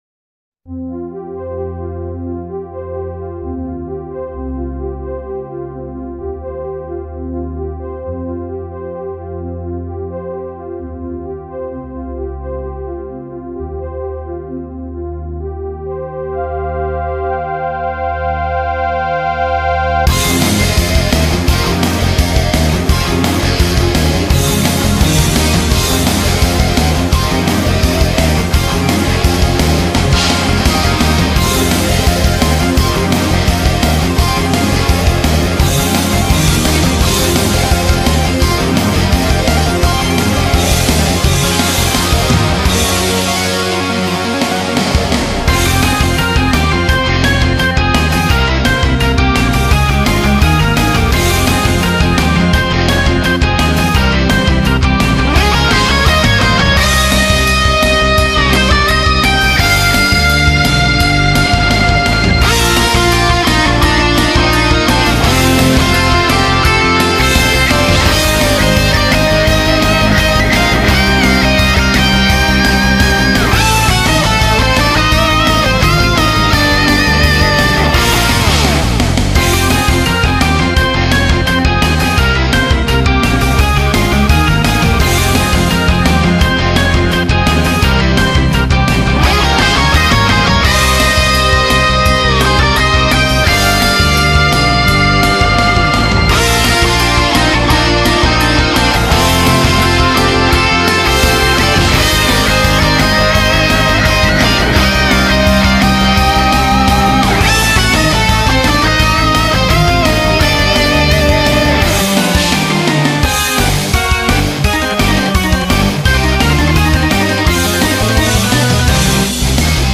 Allez, voici un classique remixé: